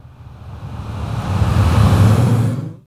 SIGHS 3REV-L.wav